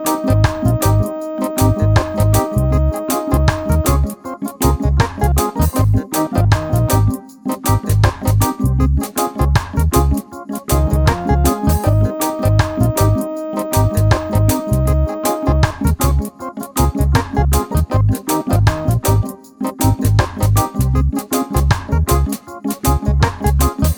minus guitar solo no Backing Vocals Reggae 4:09 Buy £1.50